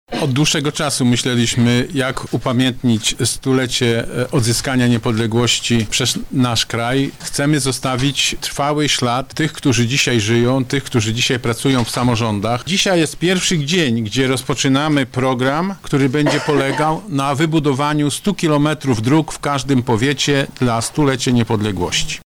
Chcemy pozostawić po sobie ślad – tłumaczy Marszałek Województwa Sławomir Sosnowski: